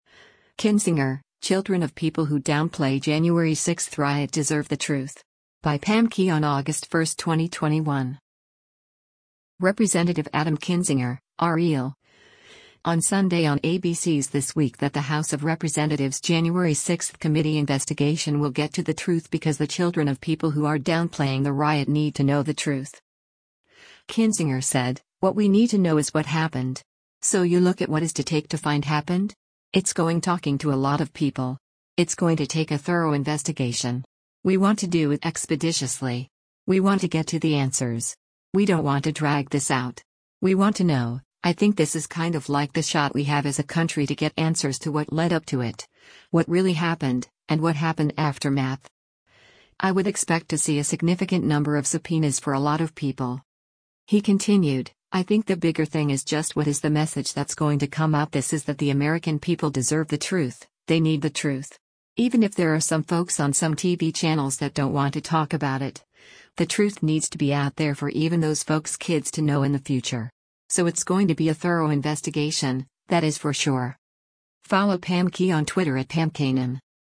Representative Adam Kinzinger (R-IL) on Sunday on ABC’s “This Week” that the House of Representatives’ January 6 committee investigation will get to the truth because the children of people who are downplaying the riot need to know the truth.